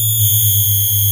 screech.ogg